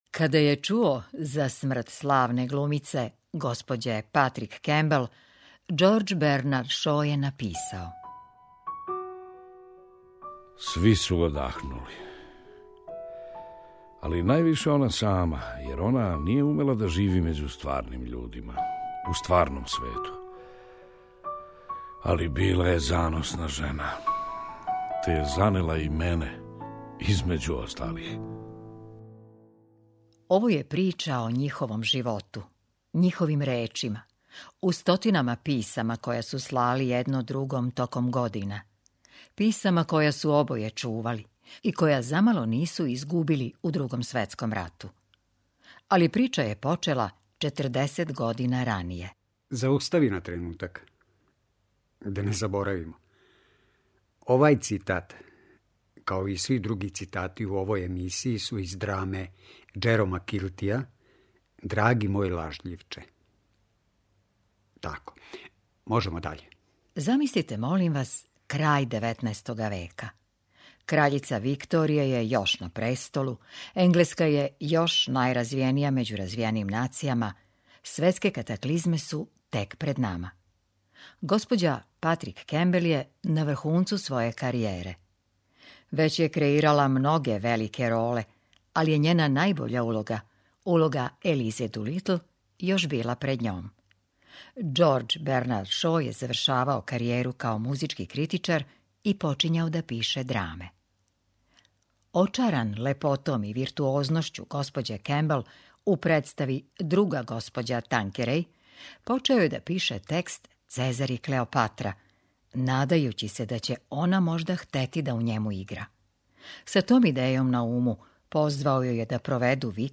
Драги мој лажљивче и као радио драма